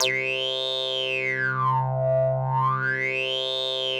C3_wasp_lead_1.wav